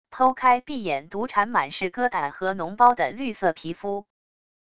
剖开碧眼毒蟾满是疙瘩和脓包的绿色皮肤wav音频